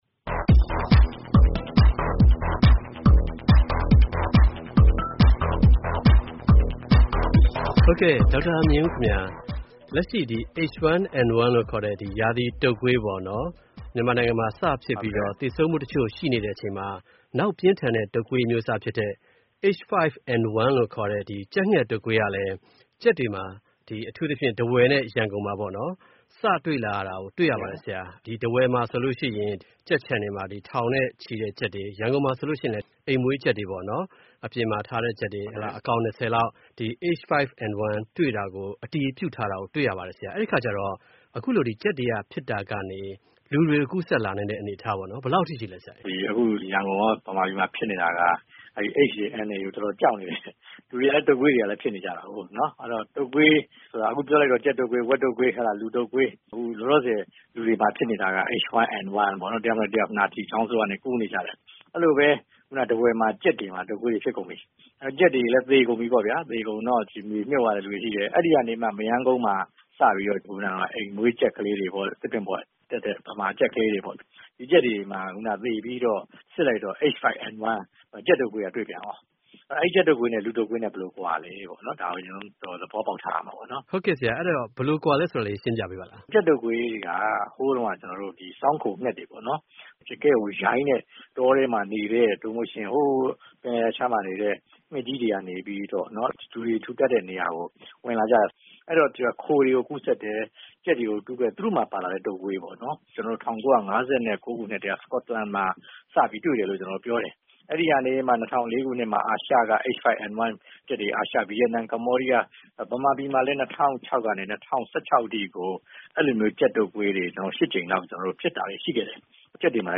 ဆက်သွယ်မေးမြန်းတင်ပြထားပါတယ်။